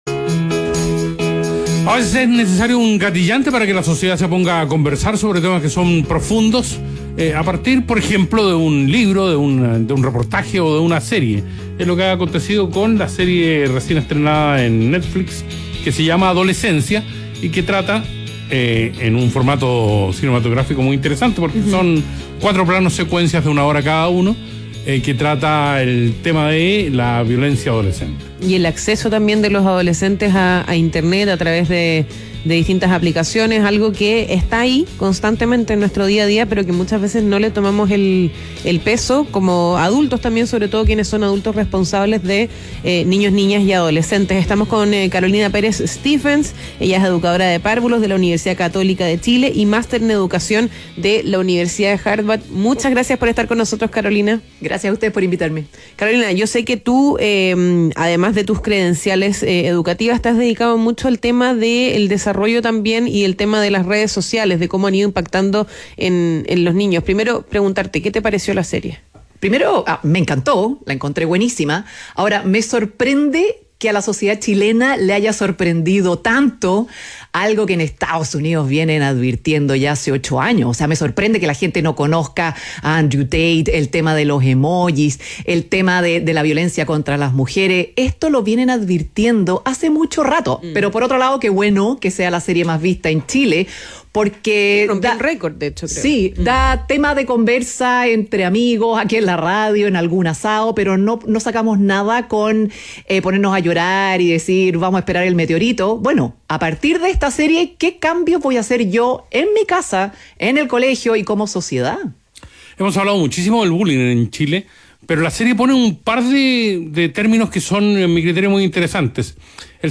Programa de conversación donde junto a un panel de invitados analizan y comentan de lunes a viernes los temas más relevantes de la actualidad nacional e internacional.